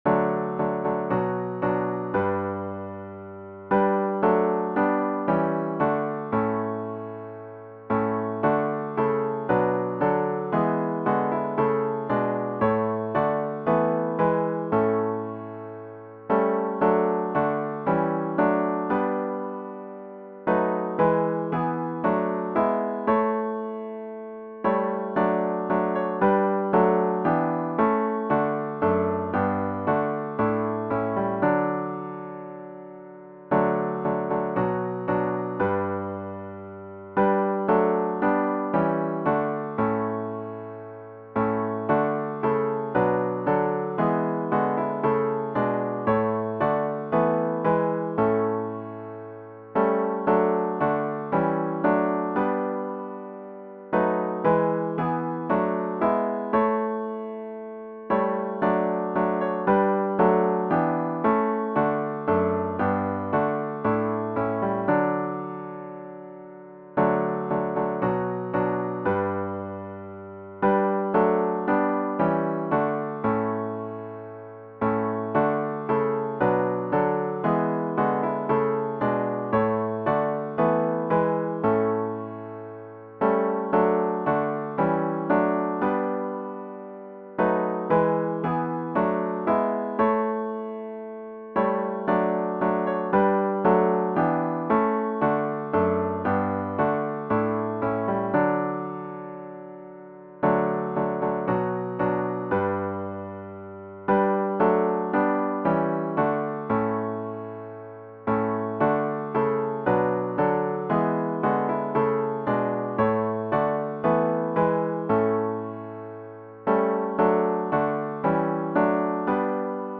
Sunday November 22 Worship service
OPENING HYMN   “Crown Him with Many Crowns”   GtG 268
zz-268-Crown-Him-with-Many-Crowns-4vs-piano-onl-y.mp3